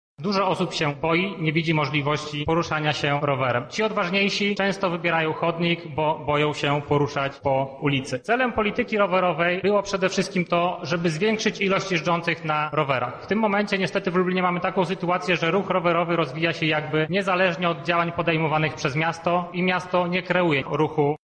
Podczas XX zwyczajnej sesji Rady Miasta mieszkańcy otrzymali możliwość zabrania głosu w dyskusji na temat rozwoju Lublina w ubiegłym roku.